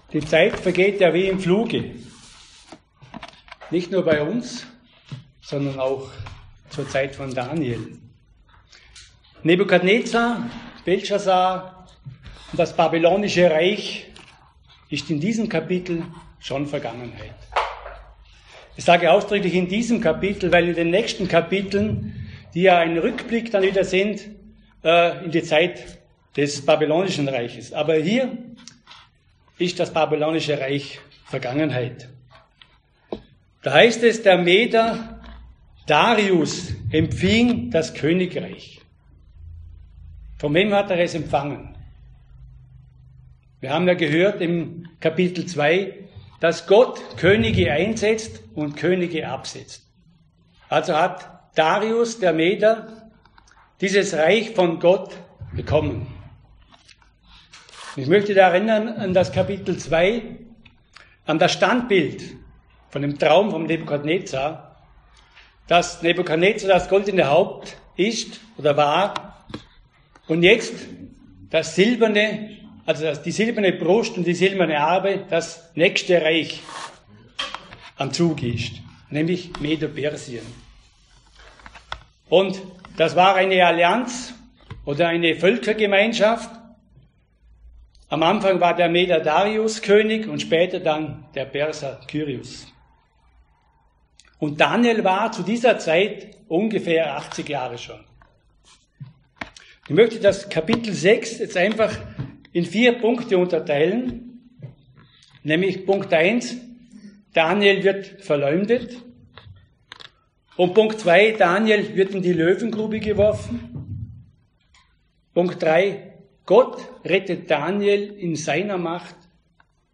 Gastpredigt